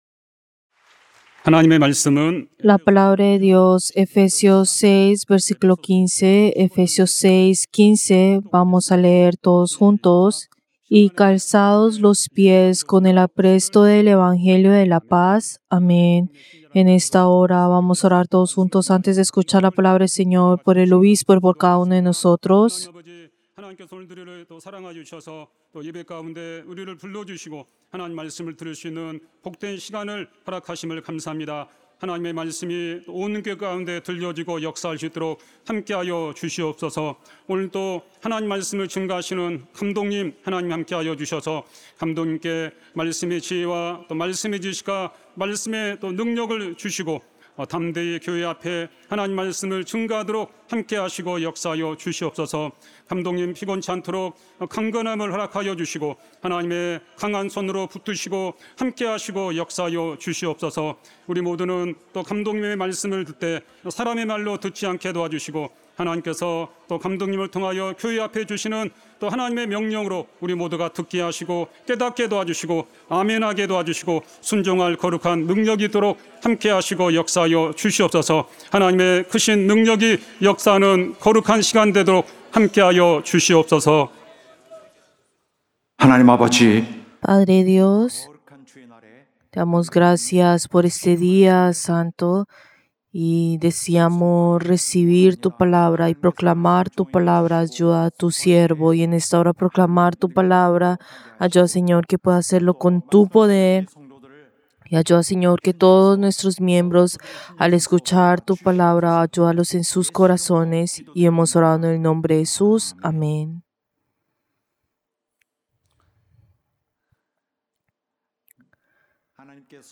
Servicio del Día del Señor del 13 de julio del 2025